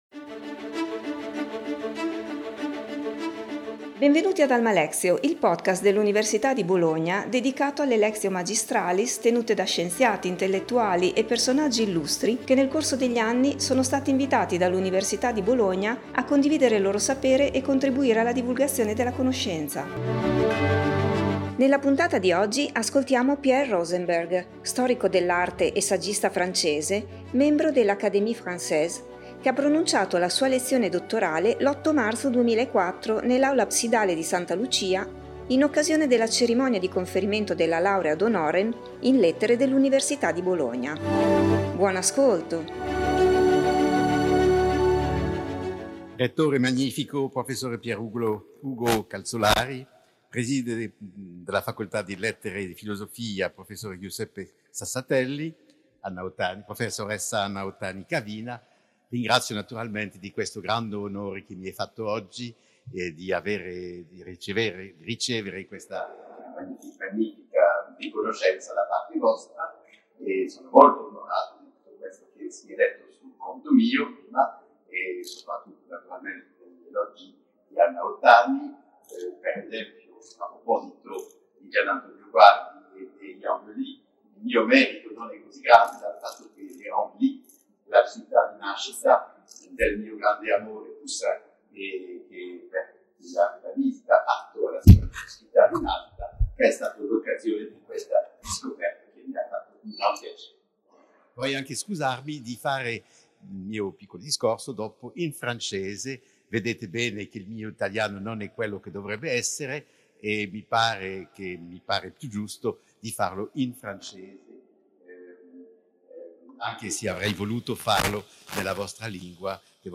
Pierre Rosenberg, storico dell'arte e saggista francese, membro dell'Académie française, e che ha condotto ad alti livelli la ricerca storico artistica con una particolare vocazione verso l’arte italiana, ha pronunciato la sua lezione dottorale l’8 marzo 2004 nell’Aula Absidale di Santa Lucia in occasione della Cerimonia di Conferimento della laurea ad honorem in Lettere dell’Università di Bologna.